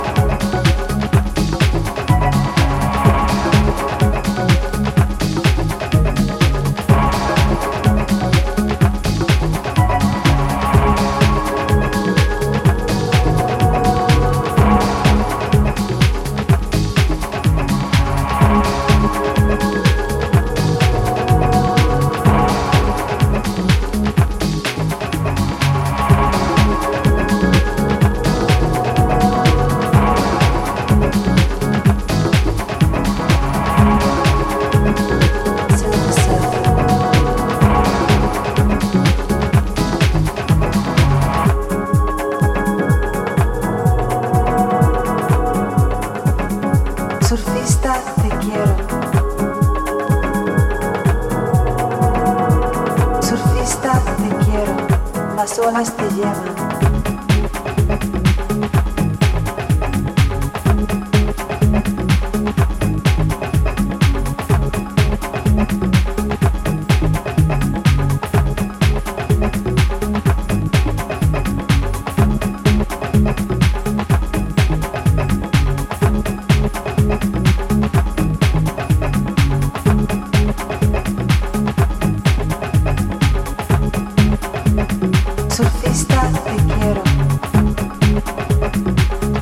supplier of essential dance music
House